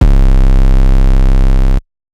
{808} way back.wav